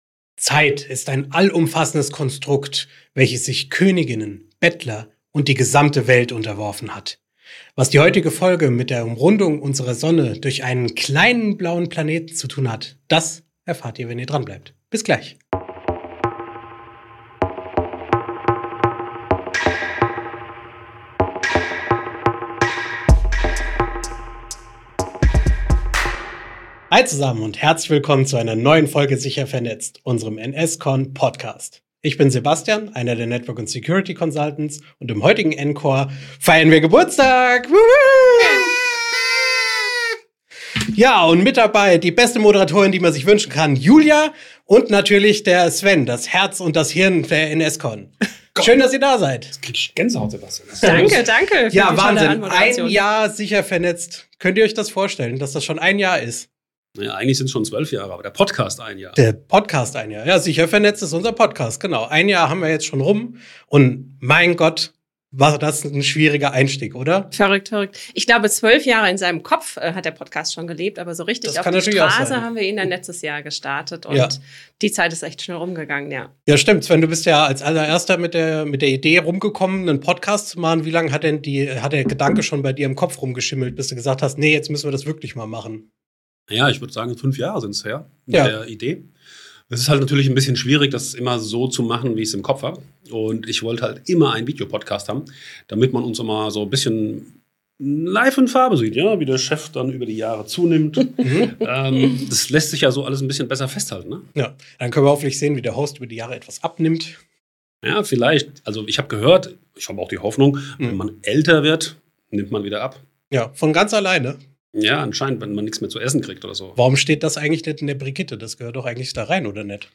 Locker, mit jeder Menge Insider-Storys, kleinen Anekdoten und dem ein oder anderen Rätsel schauen sie gemeinsam zurück – und auch mit einem Augenzwinkern nach vorn.